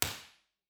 Room Impulse Response of a large recording studio
Description:  Large recording studio (52,000 ft³) at university.
With a T30 of about 0.6 sec, this room is not statistically reverberant, but it is live and diffuse.
File Type: Mono
Note the absence of flutter echos in this shoebox room.
IR_TP2_20ft_MedQ_Omni.wav